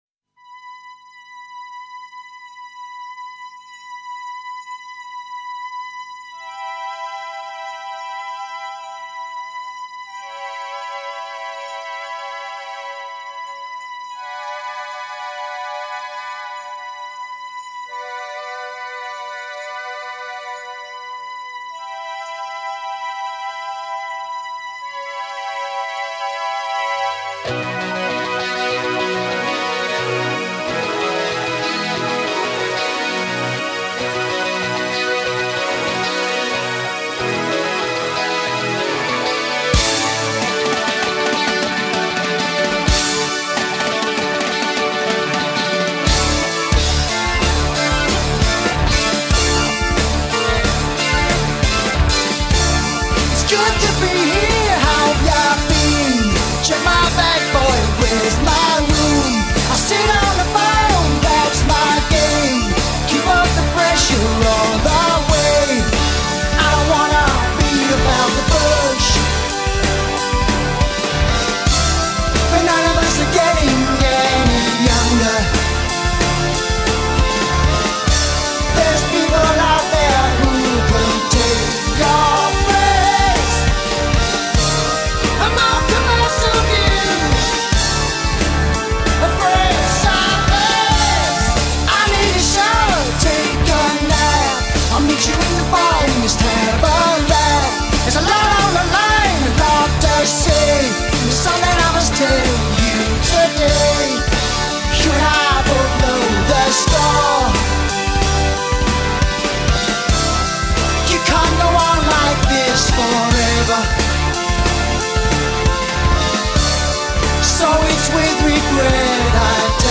Progressive Rock, Art Rock